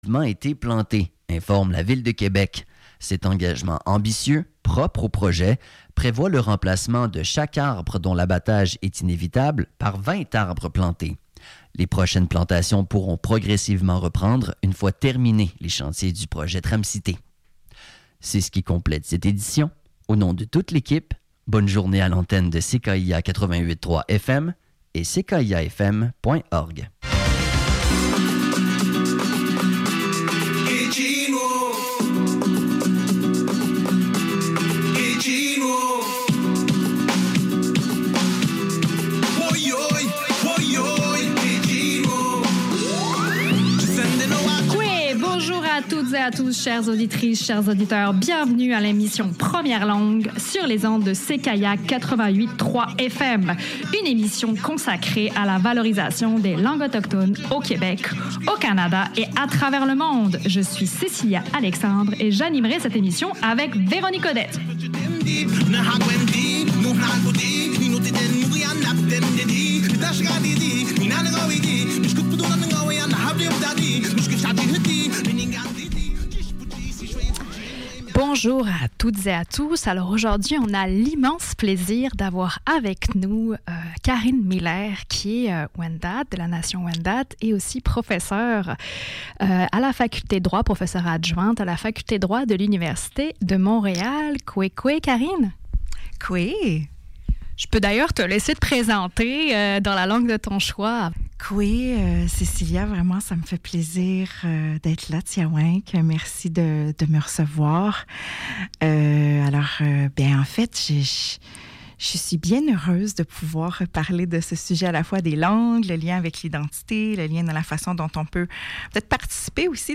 CKIA 88,3 FM - Radio urbaine